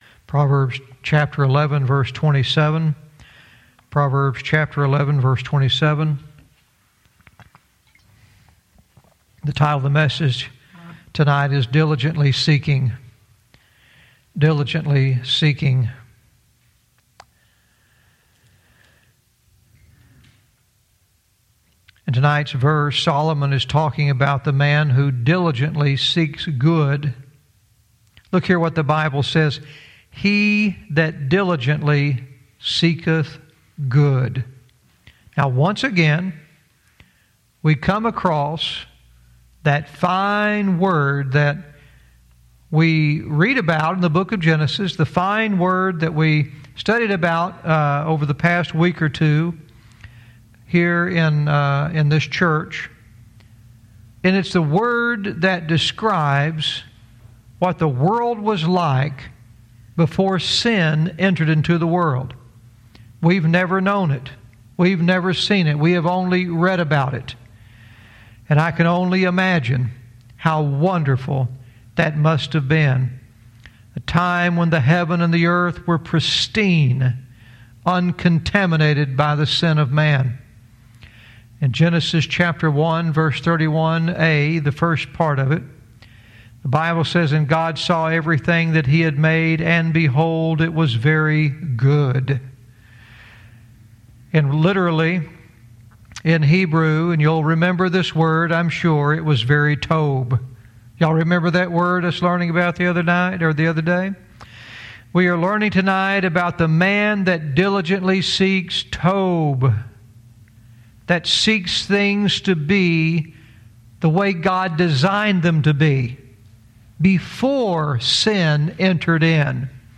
Verse by verse teaching - Proverbs 11:27 "Diligently seeking"